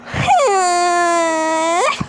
Alternative guitar